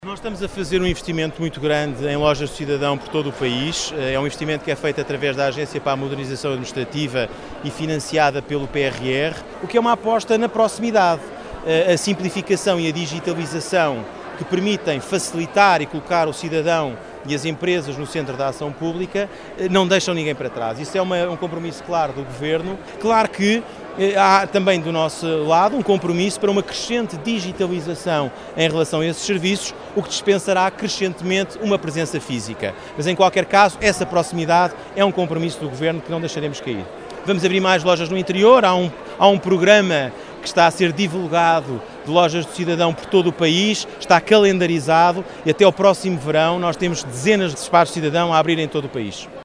Declarações do Ministro-adjunto, Gonçalo Matias, à margem do arranque da Expo Vila 4.0. O certame que, este ano, conta com 200 expositores e casa cheia.